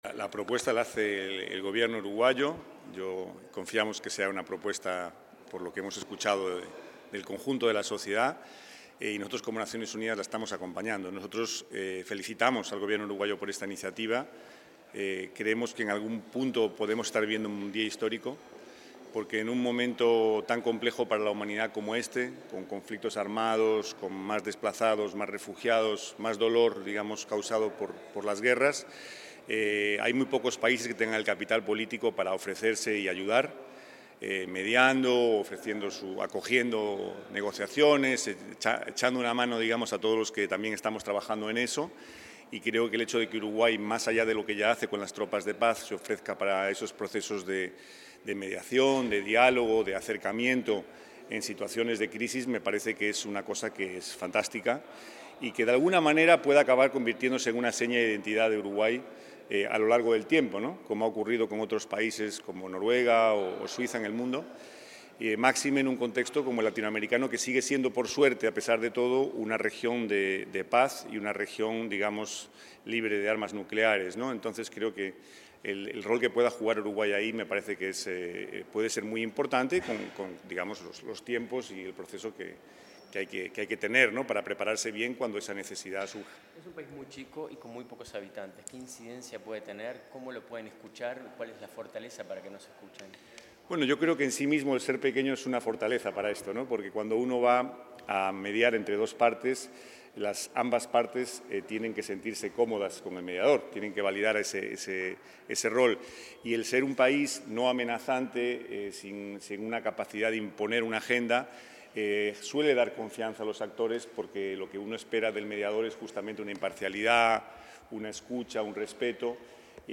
Declaraciones del coordinador Residente de las Naciones Unidas en Uruguay, Pablo Ruiz Hiebra
Declaraciones del coordinador Residente de las Naciones Unidas en Uruguay, Pablo Ruiz Hiebra 27/11/2025 Compartir Facebook X Copiar enlace WhatsApp LinkedIn En el marco del Lanzamiento: Iniciativa Uruguay promotor de paz, el coordinador Residente de las Naciones Unidas en Uruguay, Pablo Ruiz Hiebra, brindó declaraciones a la prensa.